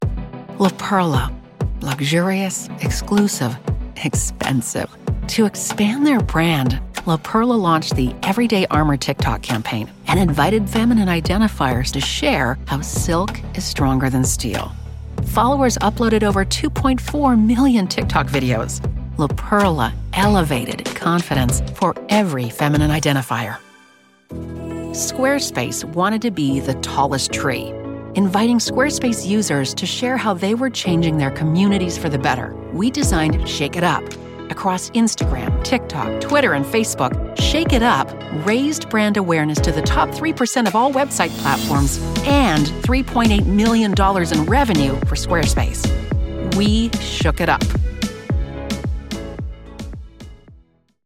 Une voix confiante et pertinente pour une narration d'entreprise.
Avec une cabine StudioBricks et un micro Sennheiser, je produis efficacement un son de qualité studio.
Sennheiser 416 ; cabine StudioBricks ; Adobe Audition ; mixeur Yamaha AG03 ; Source-Connect.